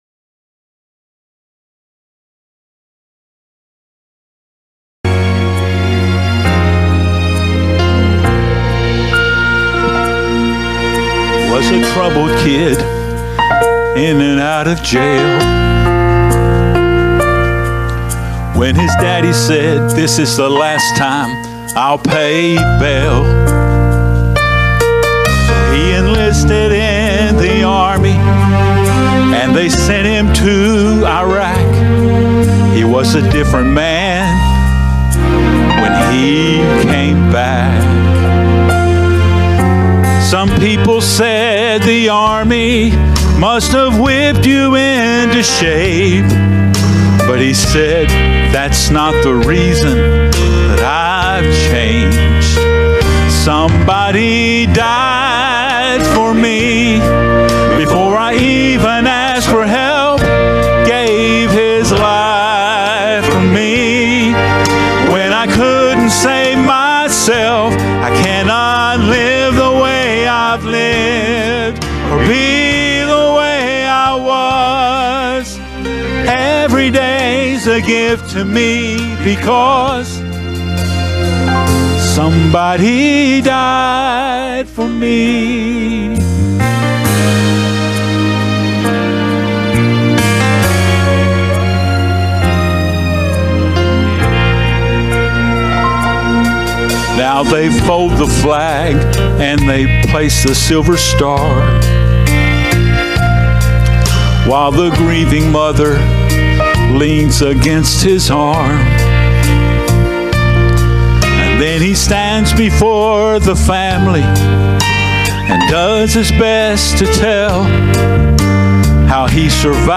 Sermons | Macedonia Baptist Church